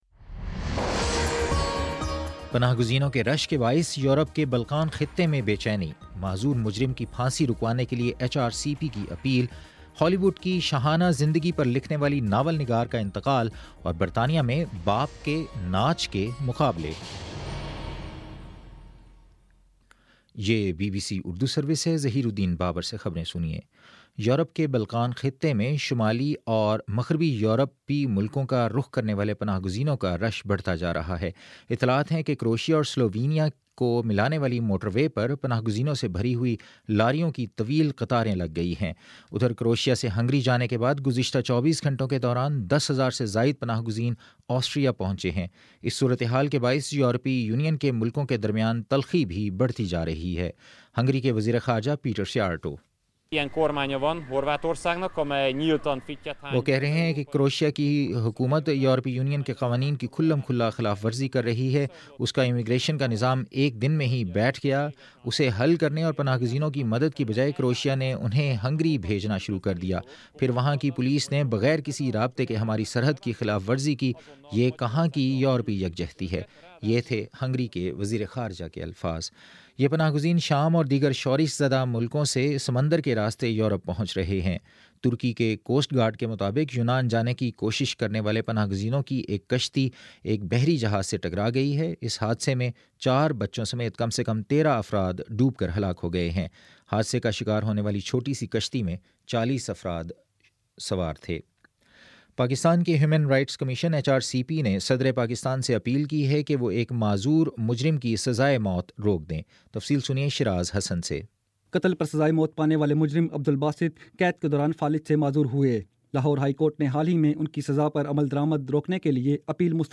ستمبر20 : شام چھ بجے کا نیوز بُلیٹن